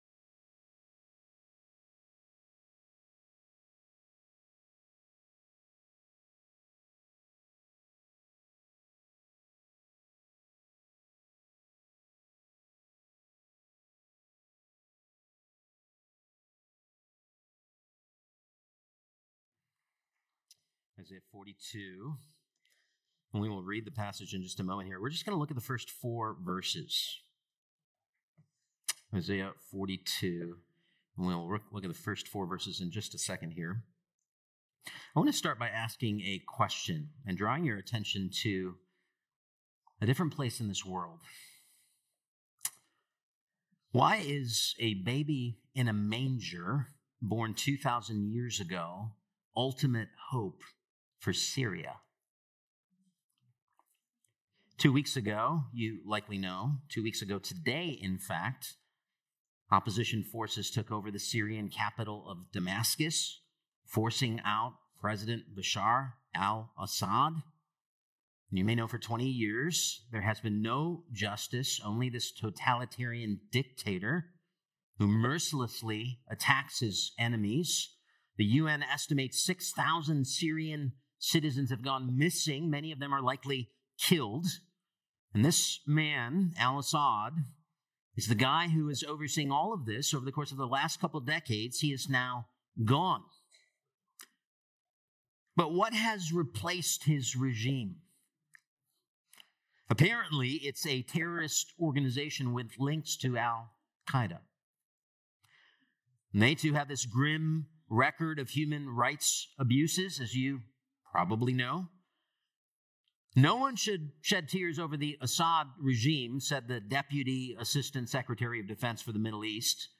Faith Church Sermon podcast